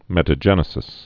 (mĕtə-jĕnĭ-sĭs)